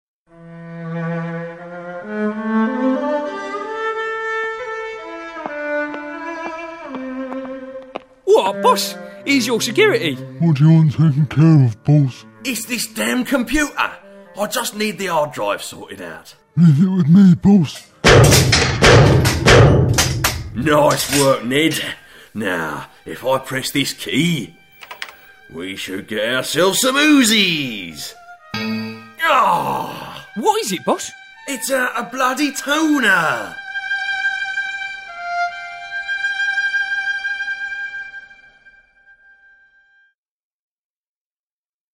The concluding part of the epic 3D Printer sketch - will boss get to make his uzis?